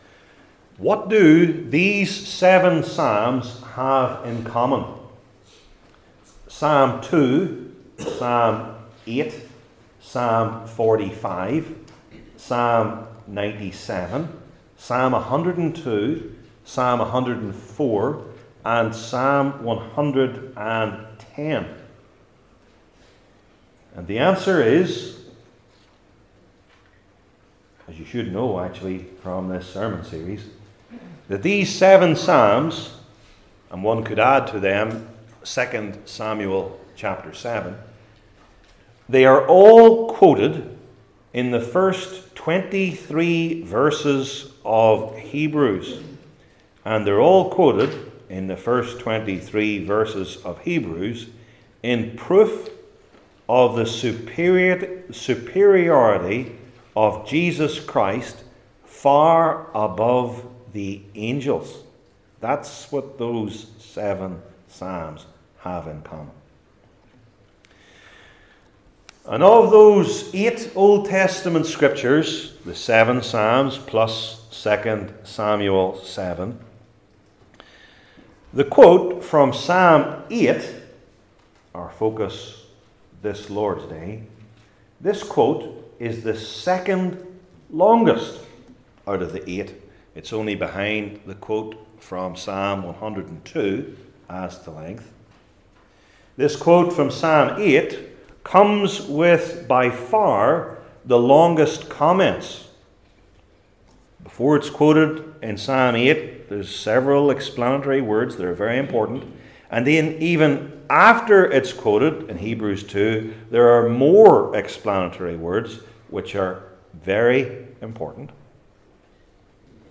Hebrews 2:5-9 Service Type: New Testament Sermon Series I. The Prefall Adam?